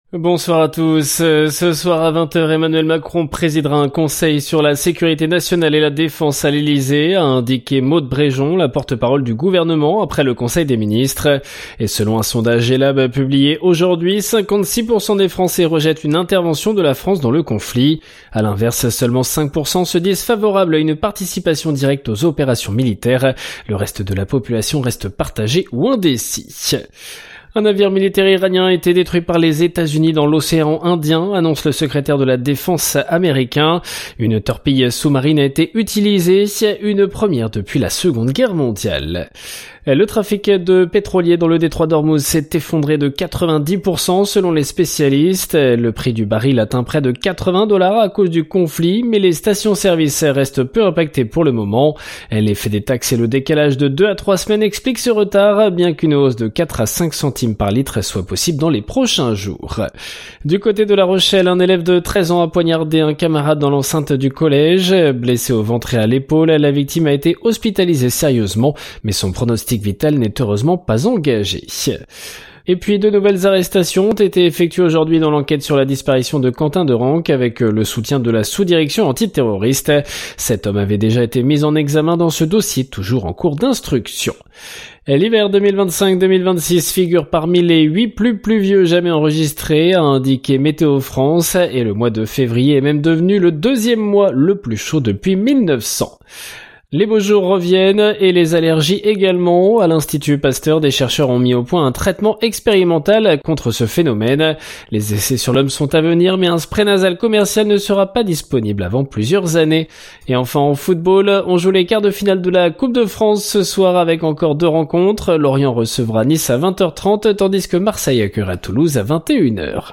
Flash infos 04/03/2026